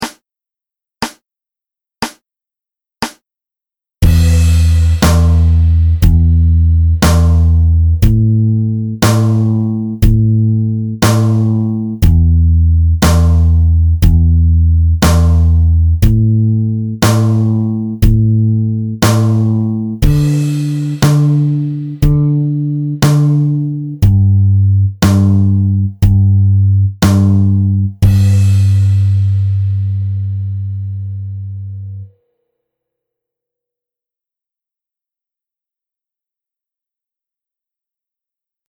The progression is as follows: Em-Am-Em-Am-Dm-G. We just learned the minor chords and the final G major chord is from the previous section, 5 Open-String Major Chords.
This rhythmic strum pattern for this is called the Charleston rhythm. The pattern is played on beat 1 and the and of 2 and beat 4.
There’s also a backing track for you to practice along with.